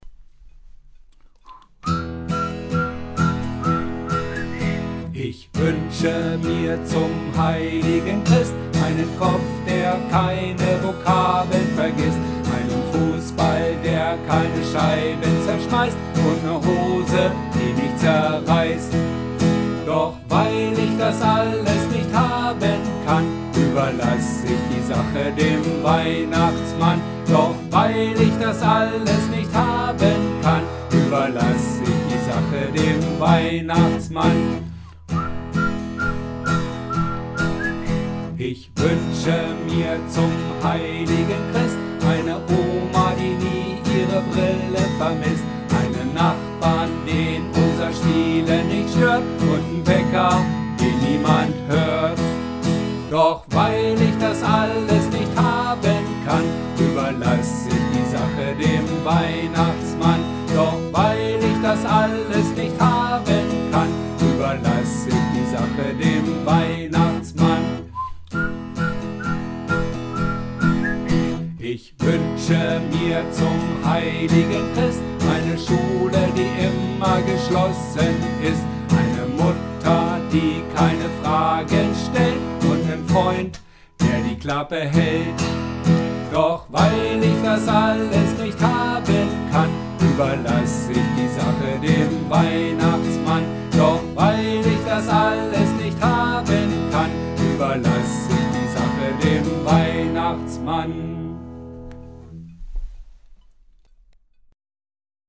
(Gitarre + Gesang (zweifach), unbearbeitet)